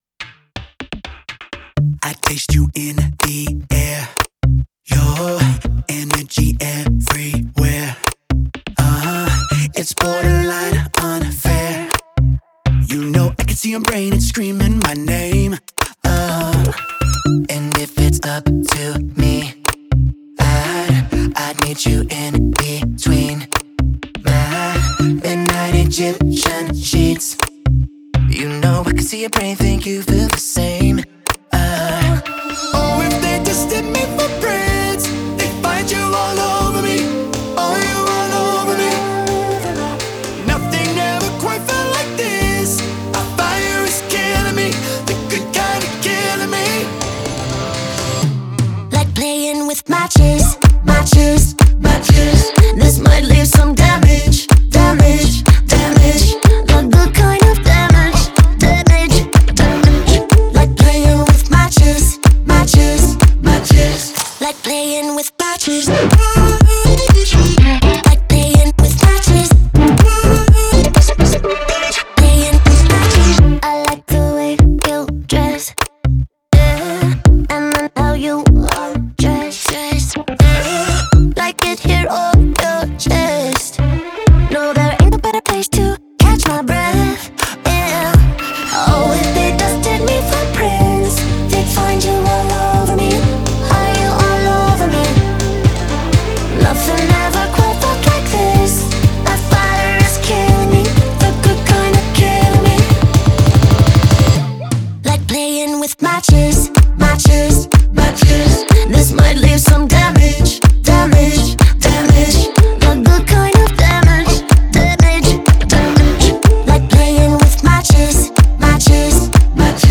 это энергичная поп-песня в стиле 2000-х